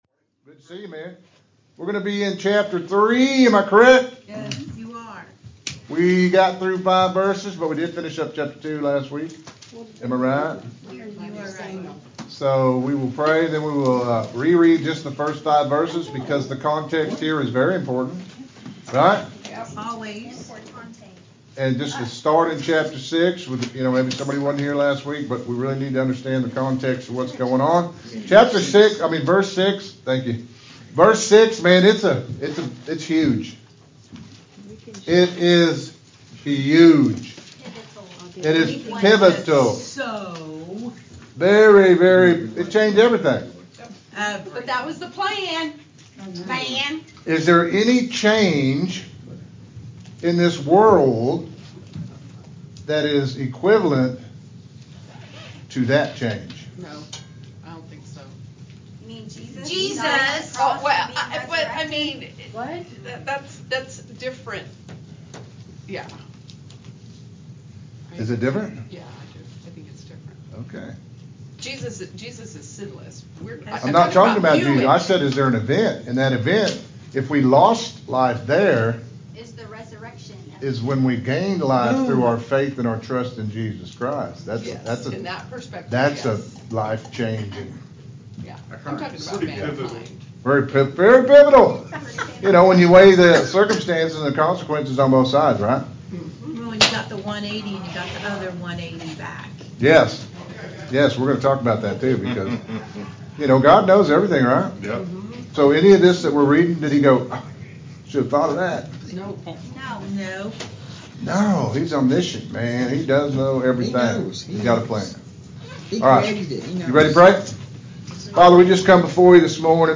Interactive Bible Study
Sermon Audio